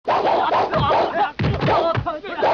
打斗的声音 打斗音效
【简介】： 打斗的声音、打斗音效